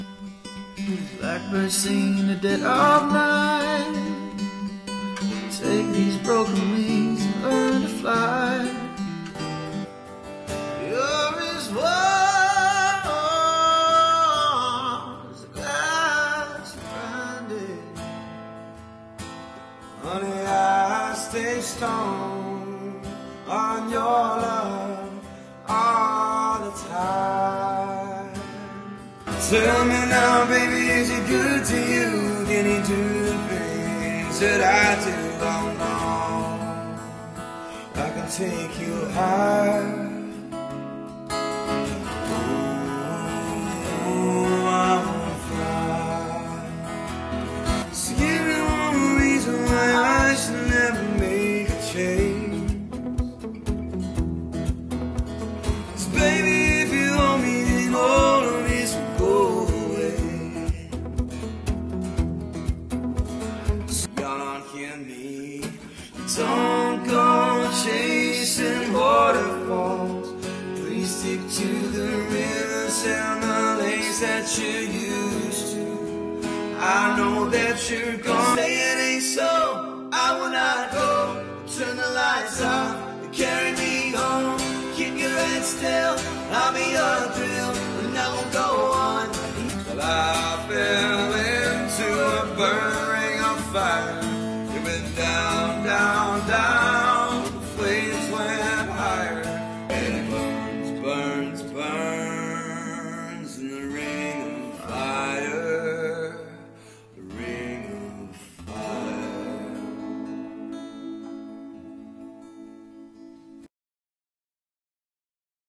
Dual Vocals | Acoustic & Electric Guitar | Looping | DJ | MC | Full Band Option